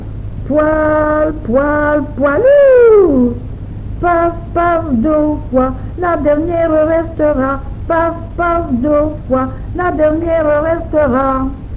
Genre : chant
Type : comptine, formulette
Lieu d'enregistrement : Jolimont
Support : bande magnétique